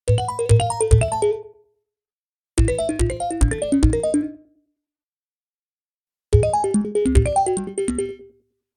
04174 mistake game stingers
bad ding fail game game-over lost miss mistake sound effect free sound royalty free Sound Effects